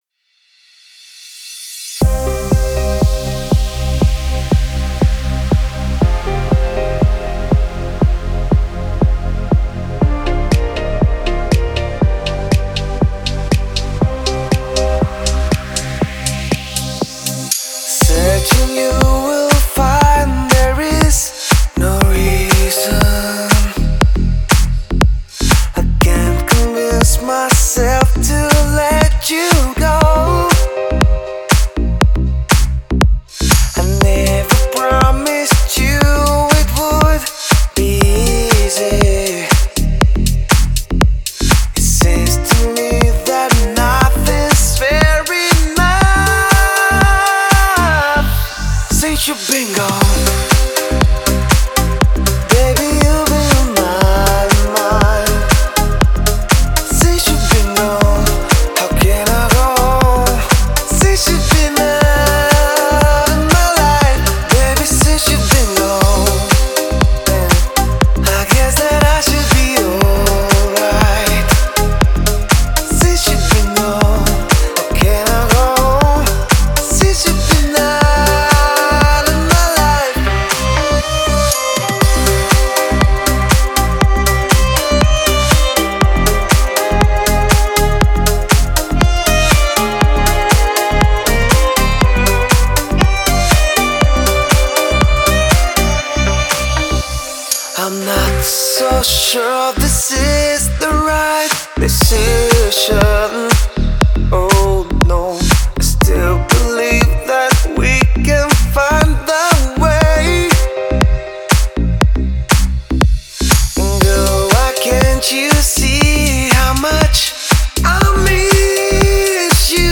это динамичная танцевальная композиция в жанре EDM.